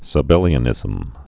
(sə-bĕlē-ə-nĭzəm)